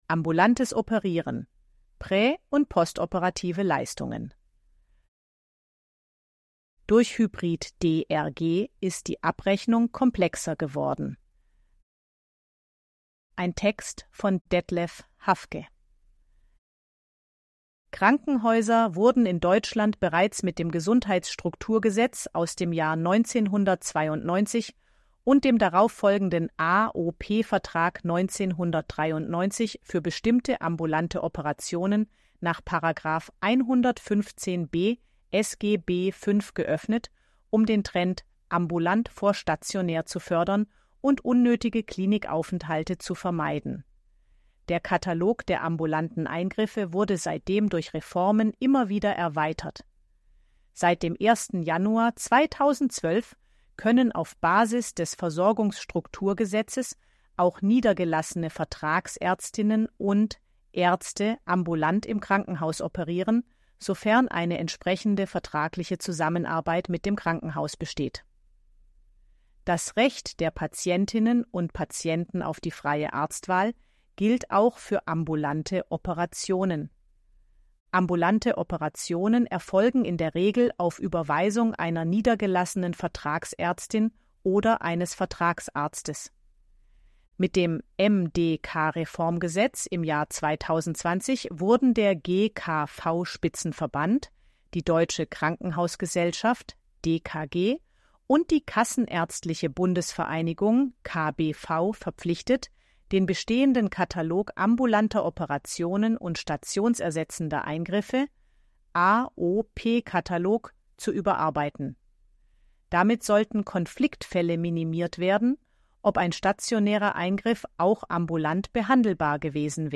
ElevenLabs_KVN261_19_Leonie.mp3